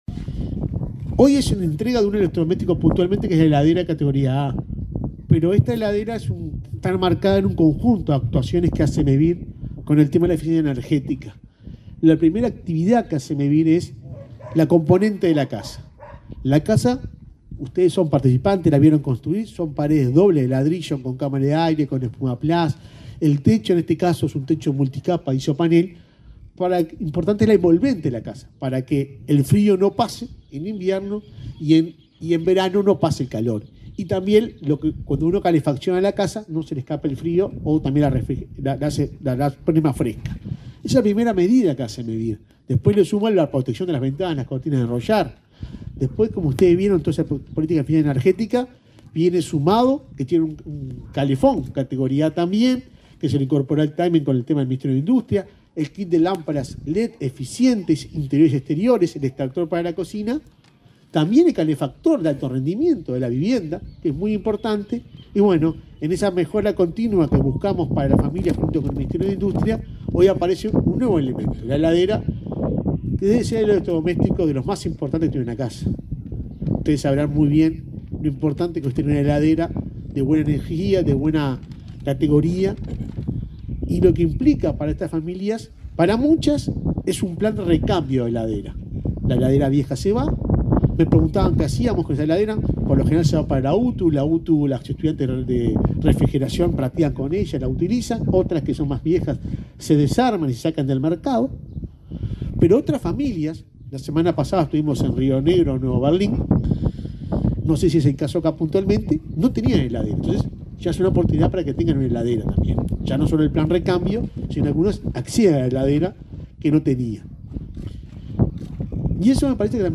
Palabras de autoridades en acto de Mevir y Ministerio de Industria